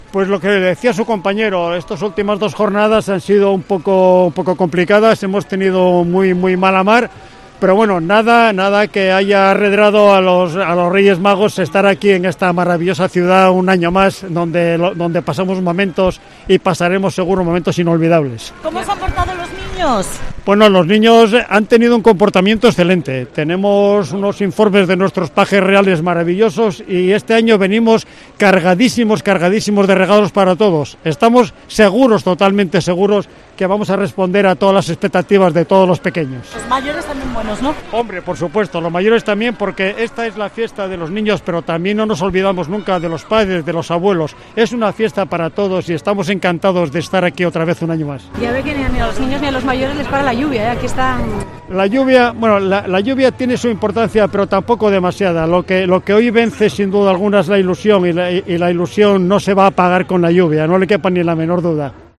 El Rey Melchor asegura que los niños de Gijón han sido muy buenos y que vienen cargados de regalos